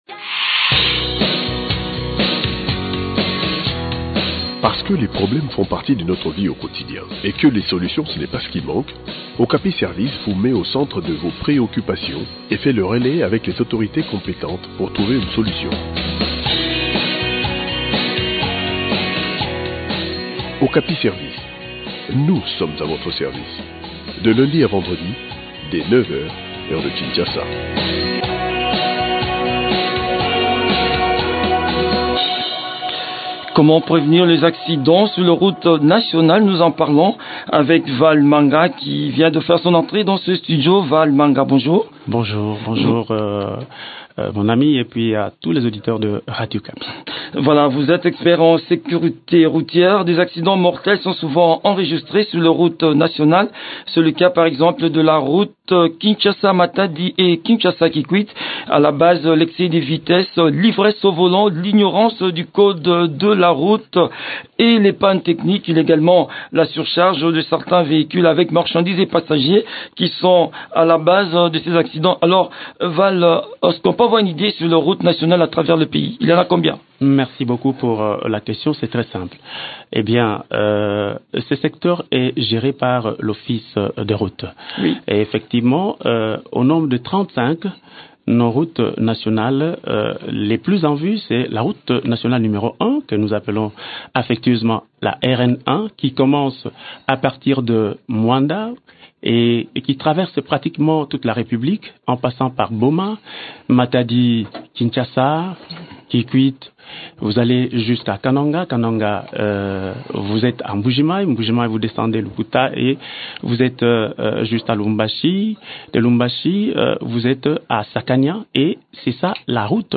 expert en sécurité routière.